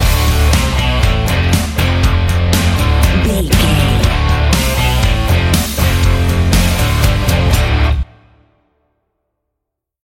Aeolian/Minor
drums
electric guitar
bass guitar
violin
country rock